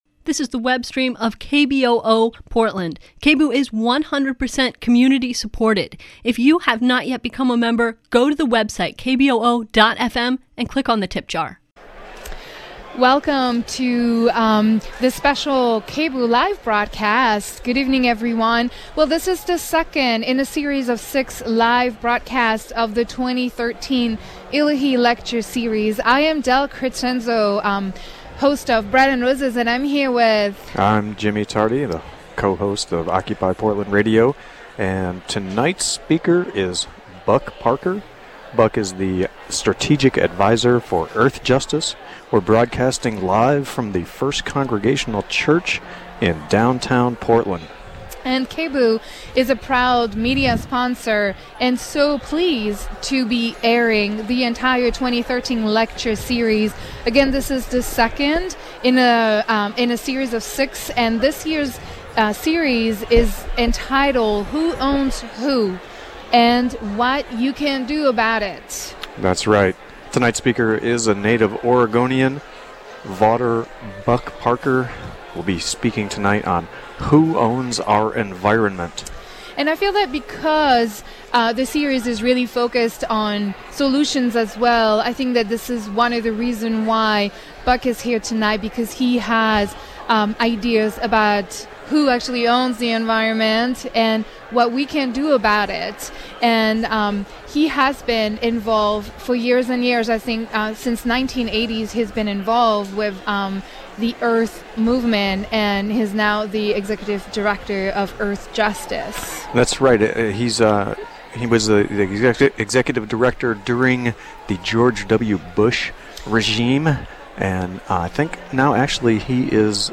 On Wednesday February 27, KBOO will continue its broadcasts from the First Congregational Church in downtown Portland, where theIllahee Lecture Series holds the second talk in its 2013 Lecture Series 'Who Owns You? and what you can do about it,'